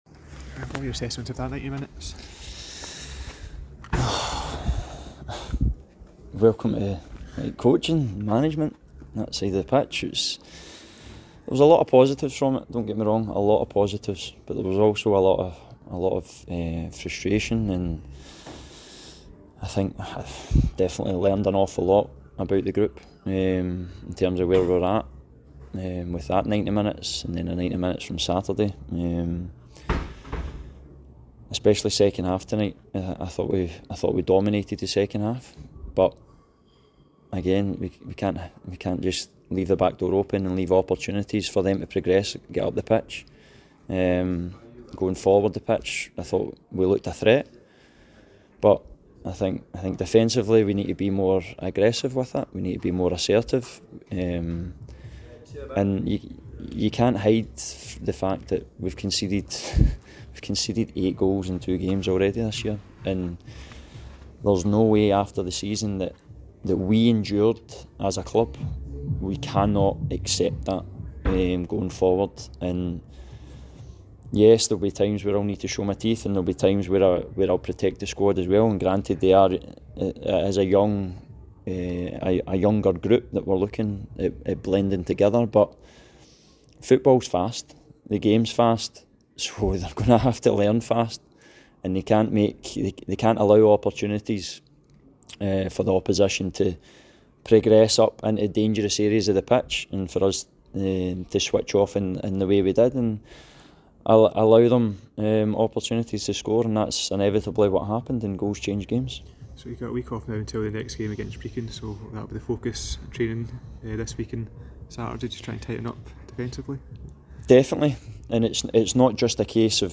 post-match comments following the Viaplay Cup fixture